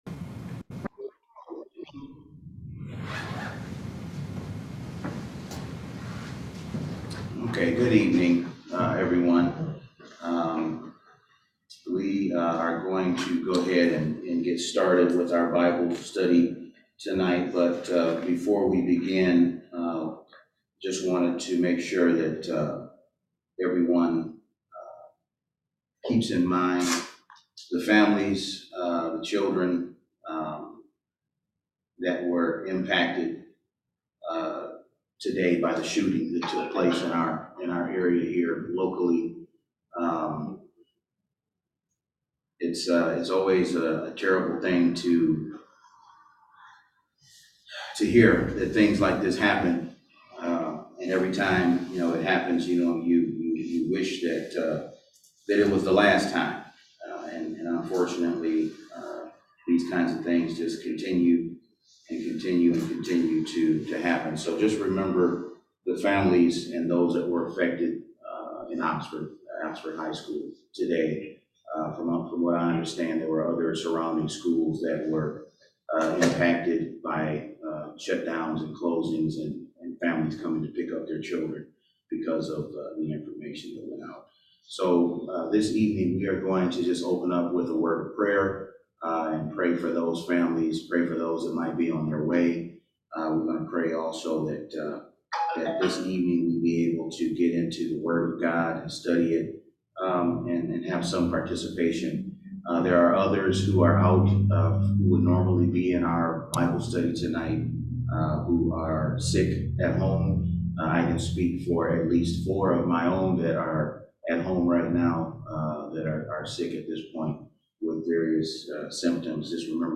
Bible Study - New Life Community Church